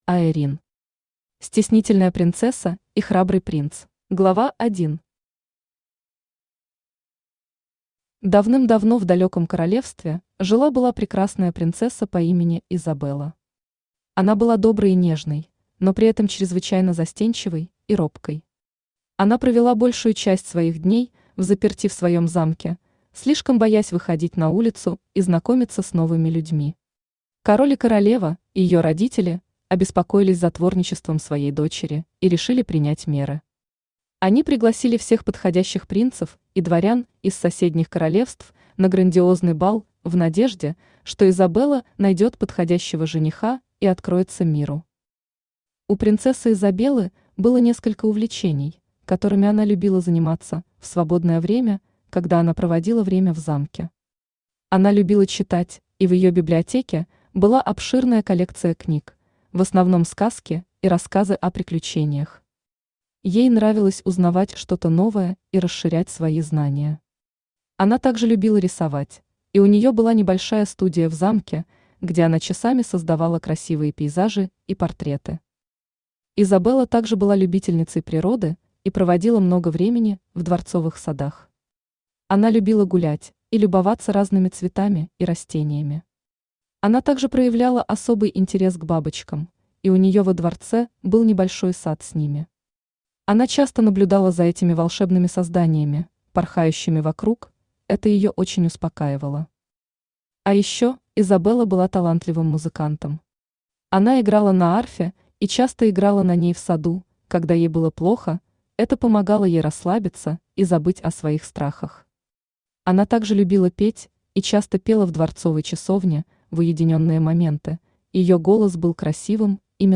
Аудиокнига Стеснительная Принцесса и Храбрый Принц | Библиотека аудиокниг
Aудиокнига Стеснительная Принцесса и Храбрый Принц Автор Аэрин Читает аудиокнигу Авточтец ЛитРес.